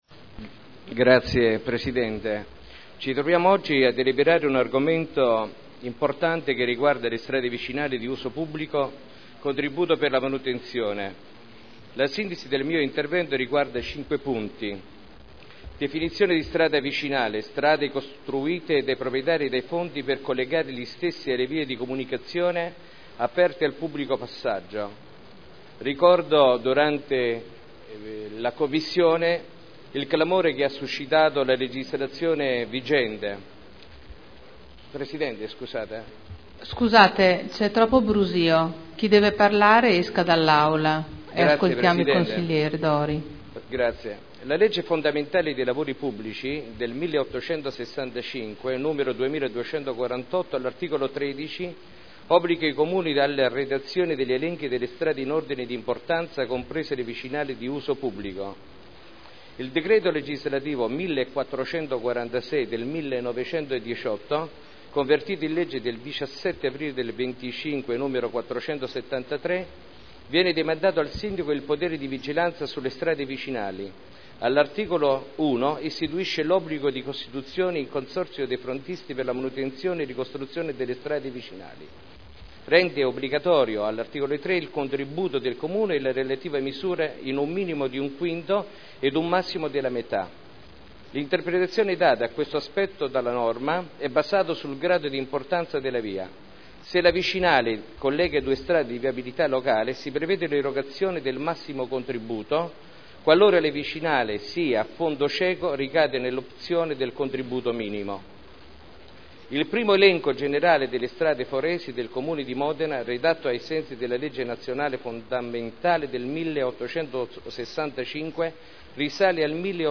Maurizio Dori — Sito Audio Consiglio Comunale
Seduta del 18 ottobre 2010 - Delibera: Strade vicinali di uso pubblico – Contributo per la manutenzione – Approvazione Piano-Programma e provvedimenti (Commissione consiliare del 5 ottobre 2010)